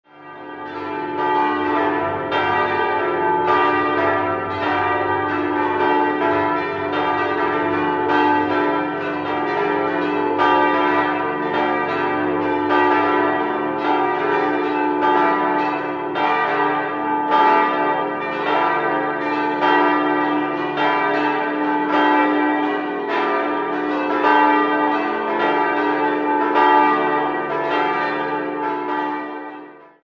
5-stimmiges ausgefülltes Salve-Regina-Geläute: d'-e'-fis'-a'-h'
d' 1.580 kg 142 cm 1984
In der Aufnahme dominiert leider die große Glocke. Vom Klang her müsste sie gekröpft aufgehängt sein.